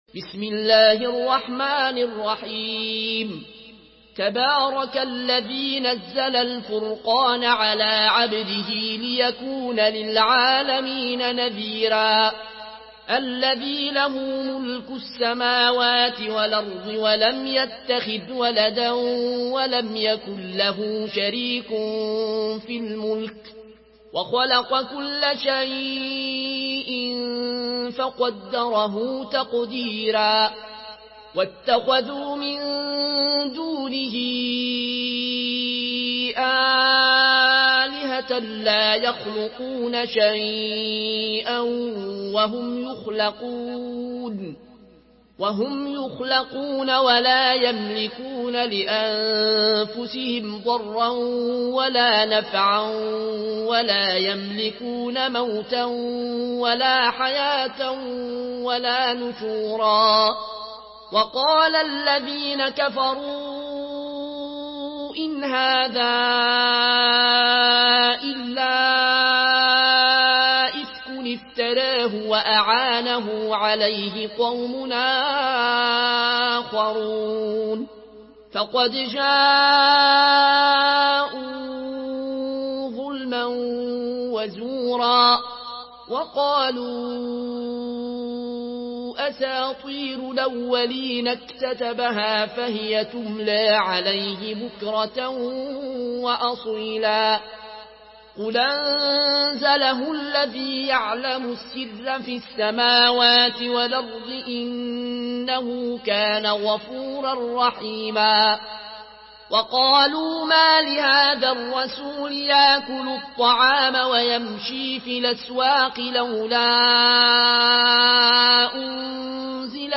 Surah Al-Furqan MP3 by Al Ayoune Al Koshi in Warsh An Nafi From Al-Azraq way narration.